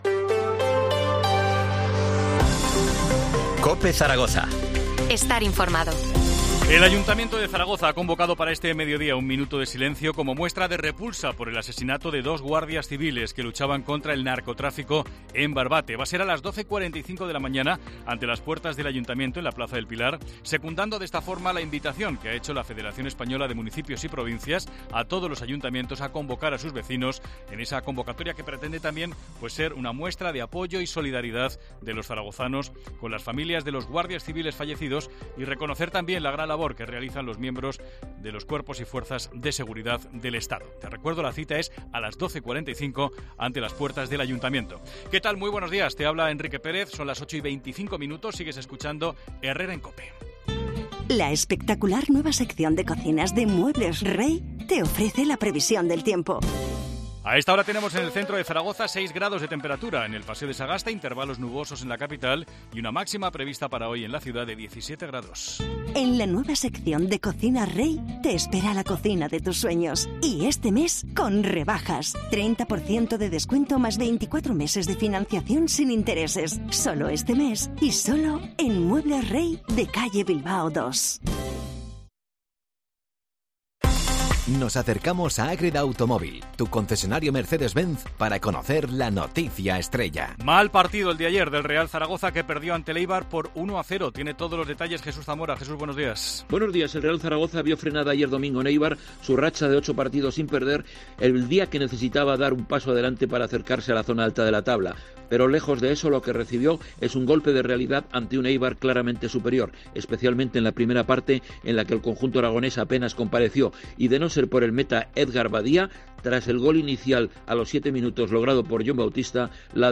Titulares del día en COPE Zaragoza